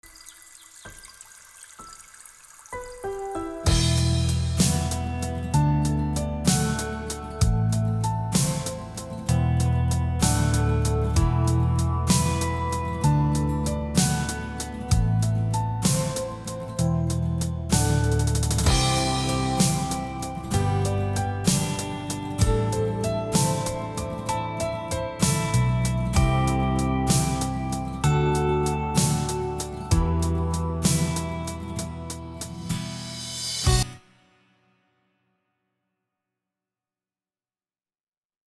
ככע.mp3 זה קטע מתוך פרויקט שאני עובד עליו בימים אלה, ניסתי להביא סאונד ואופי של תופים לייב, בוא נשמע מה אתם אומרים על התופים,